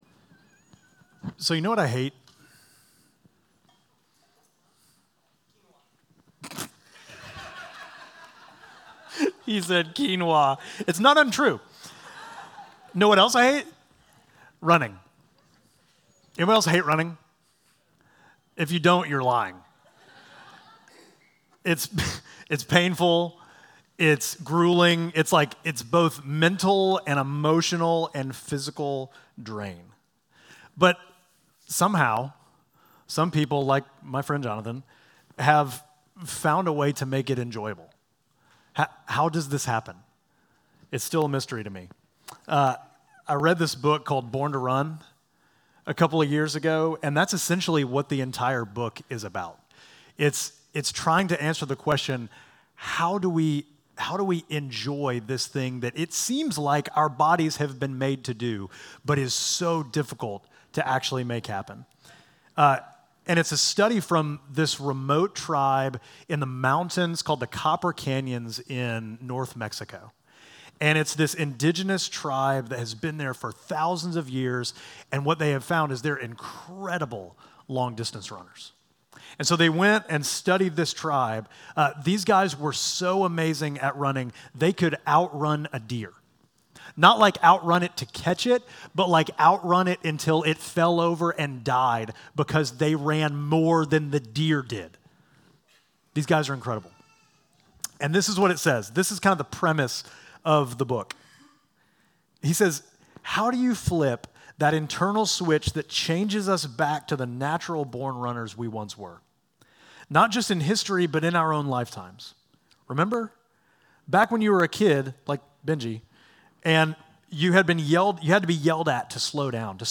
Midtown Fellowship Crieve Hall Sermons Jesus: The Unshakeable Nov 16 2025 | 00:42:21 1x 00:00 / 00:42:21 Subscribe Share Apple Podcasts Spotify Overcast RSS Feed Share Link Embed